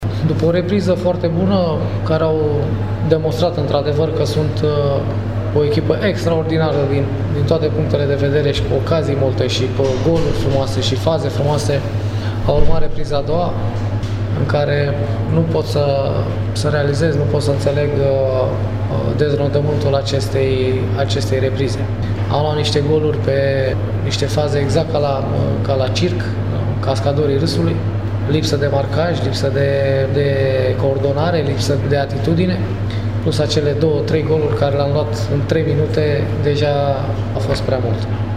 Florentin Petre a vorbit despre cele două reprize distincte ale meciului de ieri: